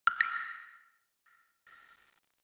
snd_ui_enterGameBtn1.wav